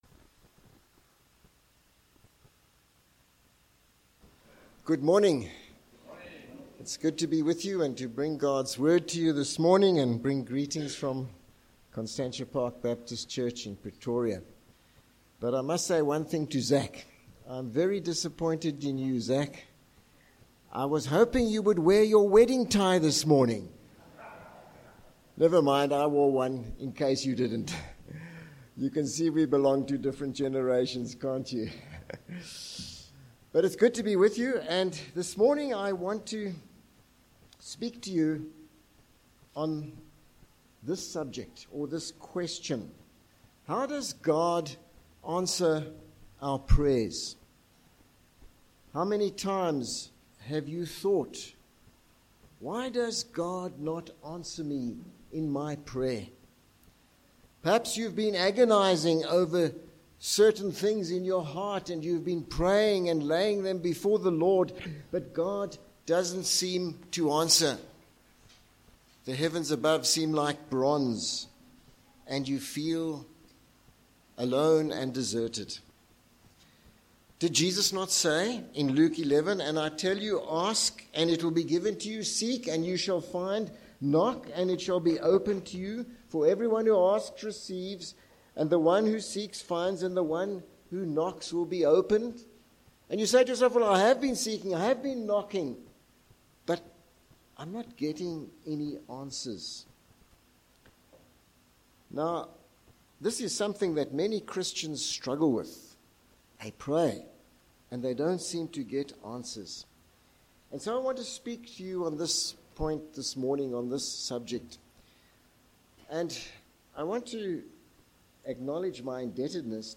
Passage: 1 John 4v13-21   Sermon points:
Service Type: Morning